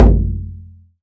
BODHRAN 1A.WAV